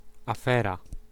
Ääntäminen
Vaihtoehtoiset kirjoitusmuodot (vanhentunut) stur Synonyymit stimulate animate incite rouse excite awaken move stir up rollercoaster spur on tintillate stooshie Ääntäminen US UK : IPA : /stɜː/ US : IPA : /stɚ/